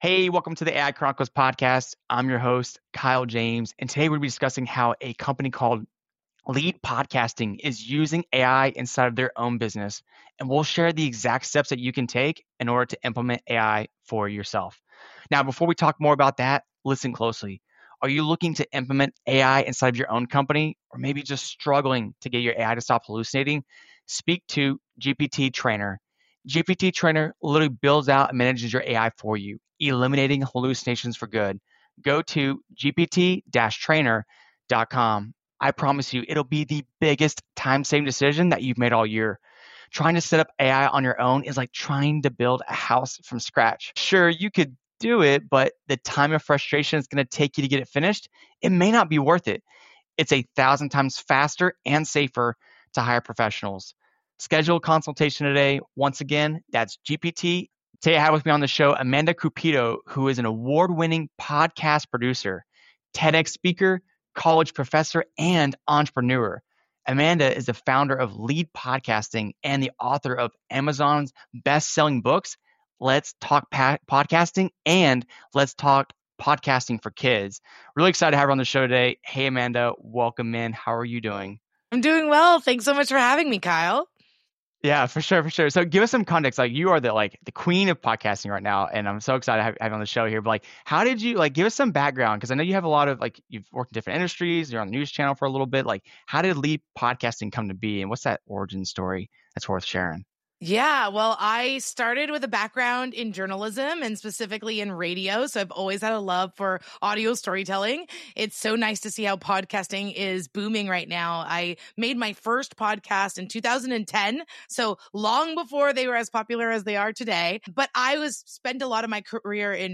They discuss the importance of host coaching for new podcasters, the various AI tools being used in podcast production, and the benefits of AI for translation and multilingual podcasts.